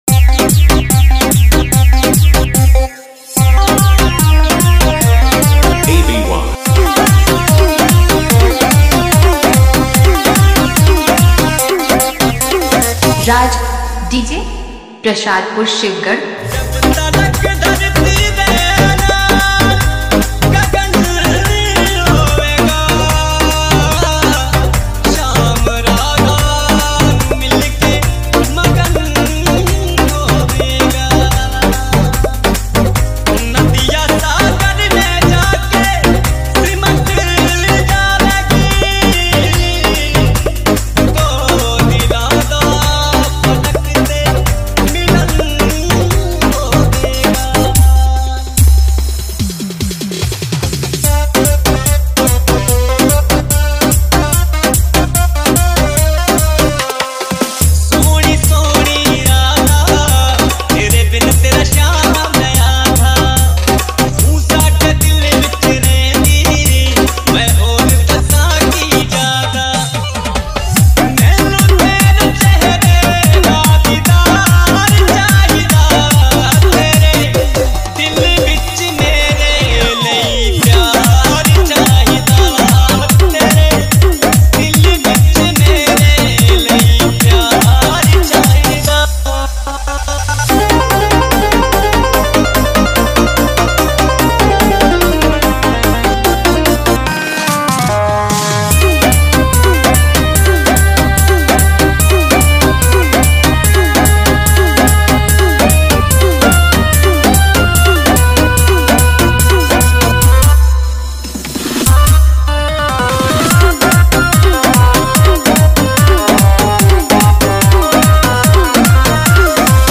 Janmashtami Dj Remix
Bhakti Remix Song